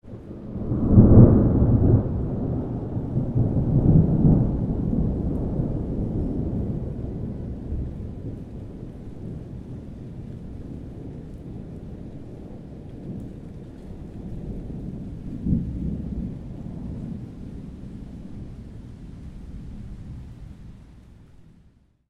thunderfar_10.ogg